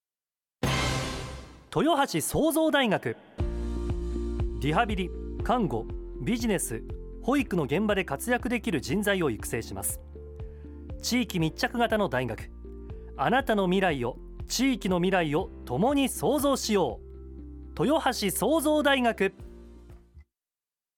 夏のオープンキャンパス開催告知　ラジオCM完成
東海ラジオ様の「ガッツナイター」（野球中継番組）内で20秒の告知CMが放送されます！